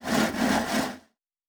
pgs/Assets/Audio/Fantasy Interface Sounds/Wood 13.wav at master
Wood 13.wav